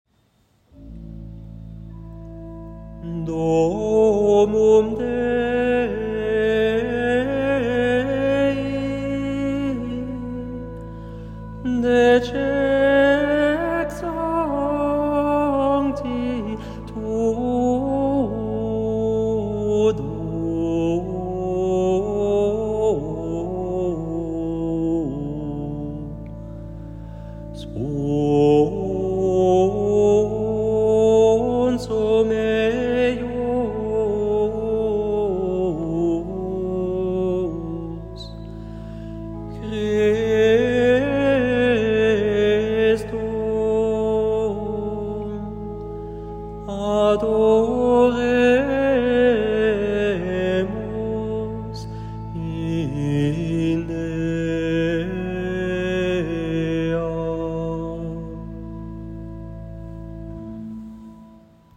Antienne invitatoire I : Domum Dei [partition LT]